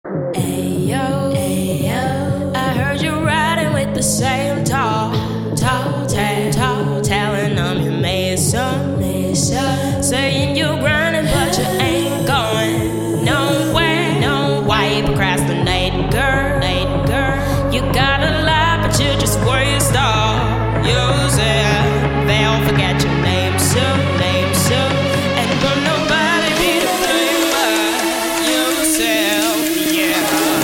• Качество: 128, Stereo
женский вокал
Хип-хоп
dance
спокойные
club